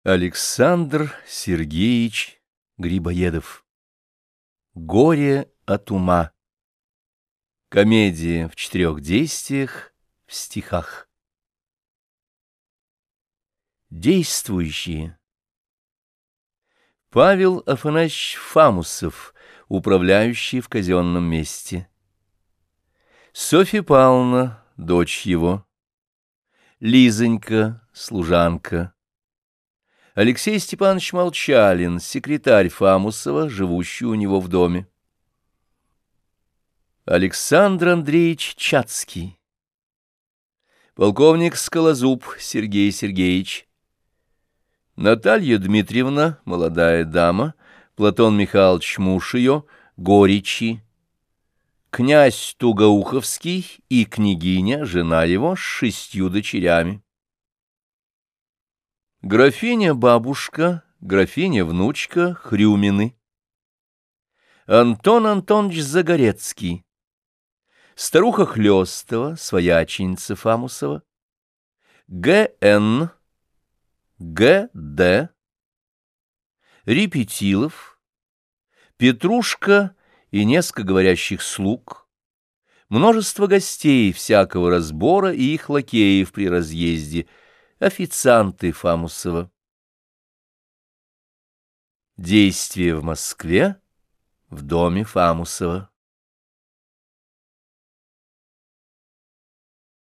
Аудиокнига Комедии | Библиотека аудиокниг